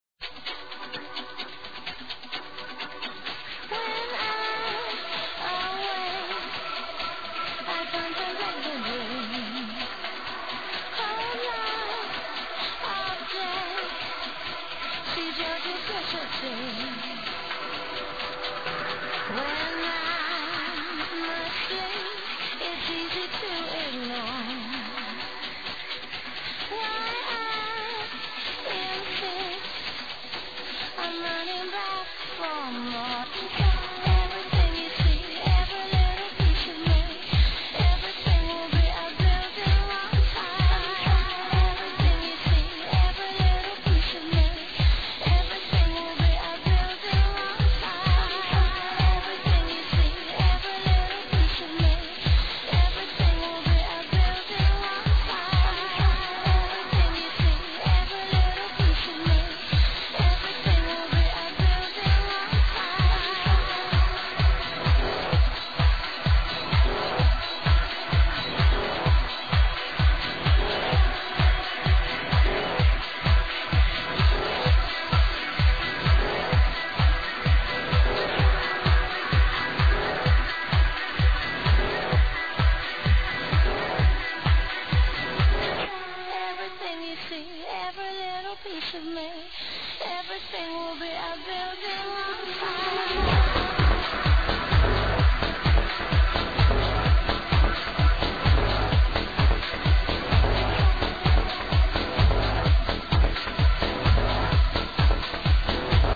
Arrow HELP ID THIS (Female Vocal)
Nice tune though, good solid beats .